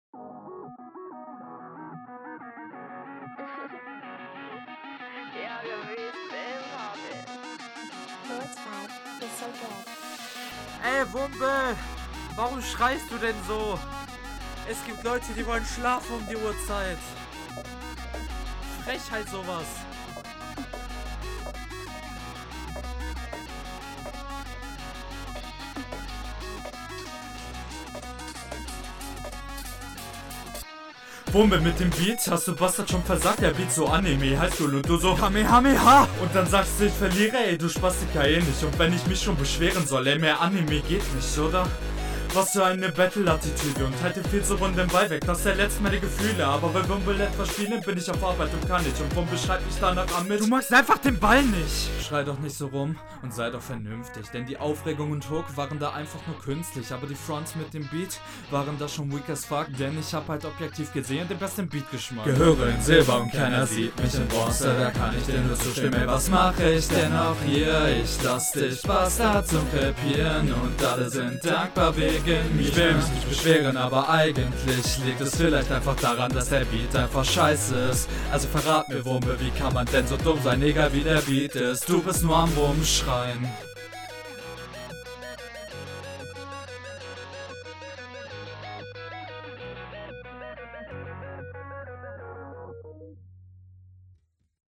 Aiaiai ich feier dich eigentlich aber die runde isses nicht Audioquali gefühlt nicht so gut …
ich mag die mische nicht so gerne aber den Gesangspart.